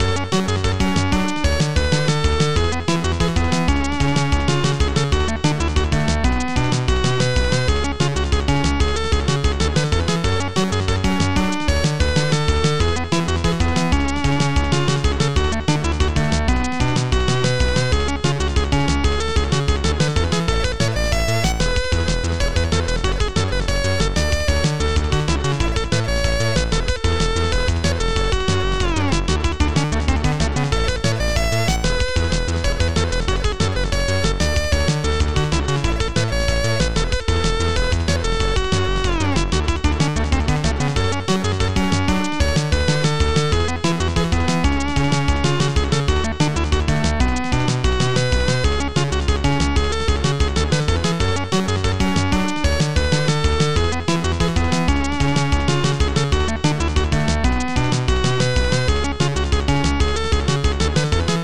Extended Module
Type xm (FastTracker 2 v1.04)